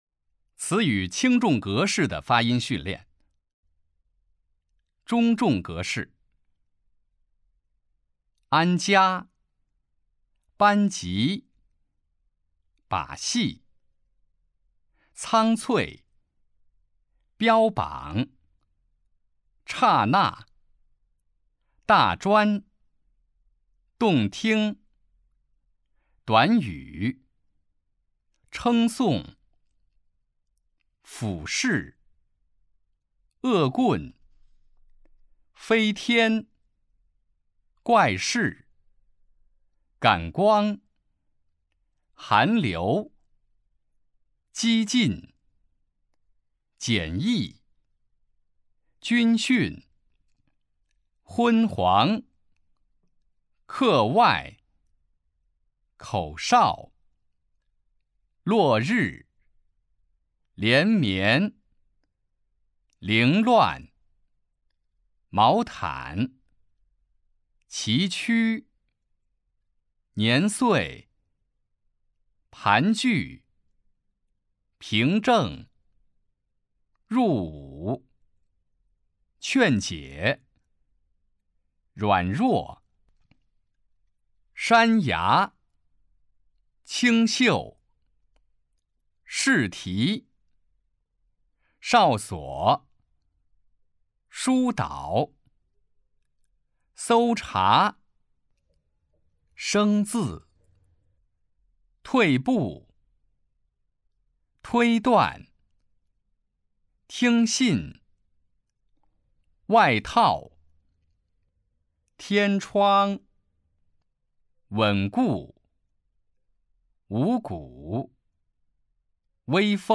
普通话水平测试 > 普通话水平测试资料包 > 02-普通话水平测试提升指导及训练音频
006词语轻重格式的发音训练.mp3